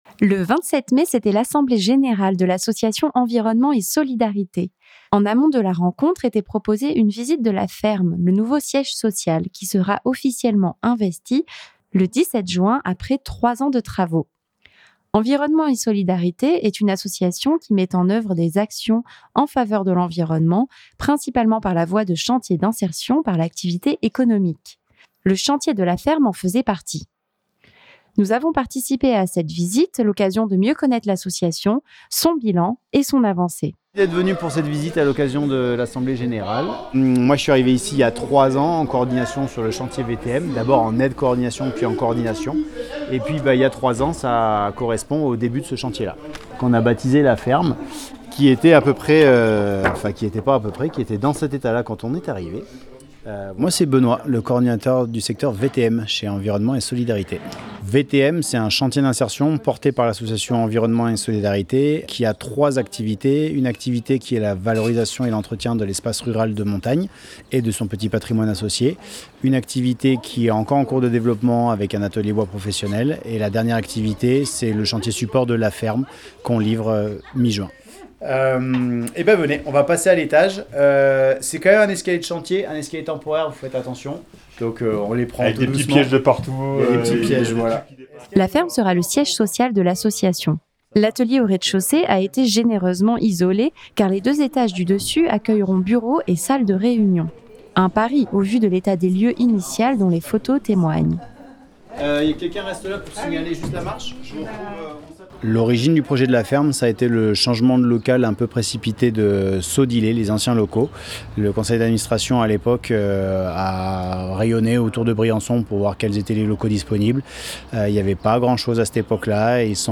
Reportage- La ferme - Environnement et Solidarité.mp3 (20.29 Mo)
Nous avons participé à cette visite, l'occasion de mieux connaitre l'association, son bilan et son avancée.